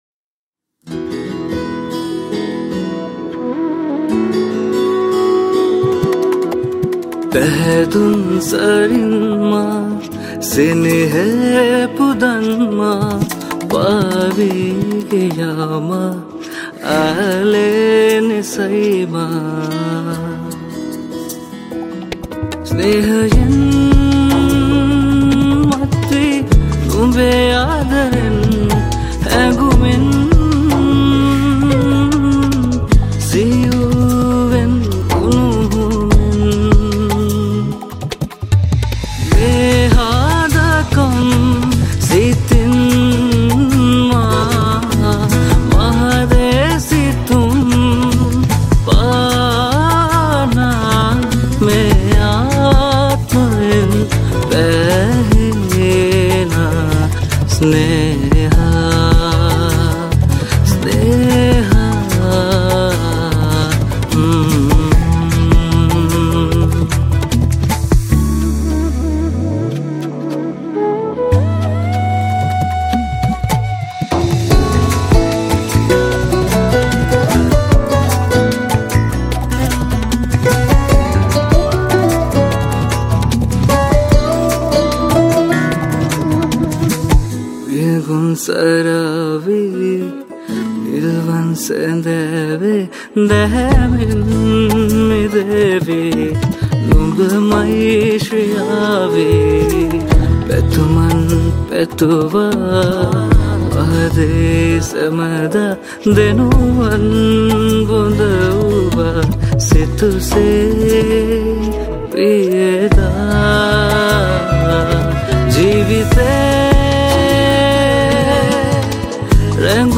Guitar / Banjo / Mandolin
Flutist